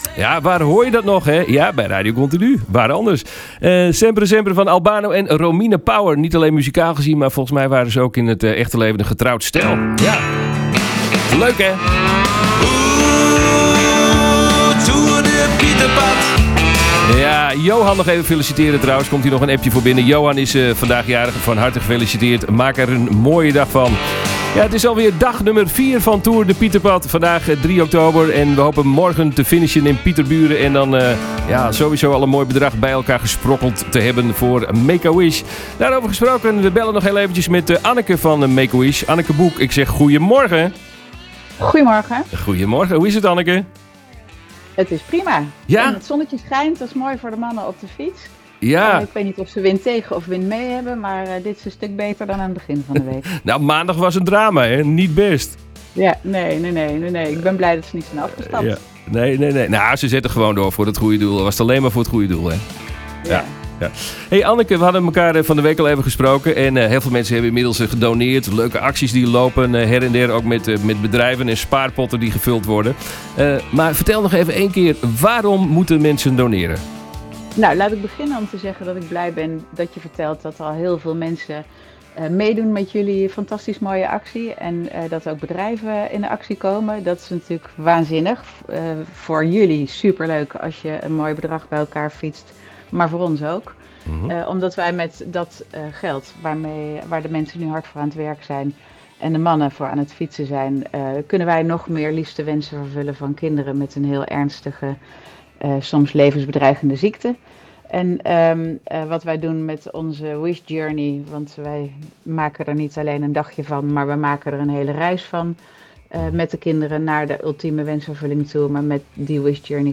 Make a Wish gesprek donderdag
We belden vanmorgen weer even met Make a Wish. Luister en hoor waarom we samen een glimlach kunnen toveren op het gezicht van zieke kinderen!